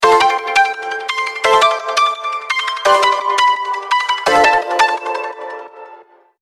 130-160 bpm